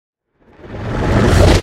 Minecraft Version Minecraft Version 25w18a Latest Release | Latest Snapshot 25w18a / assets / minecraft / sounds / mob / warden / sonic_charge4.ogg Compare With Compare With Latest Release | Latest Snapshot
sonic_charge4.ogg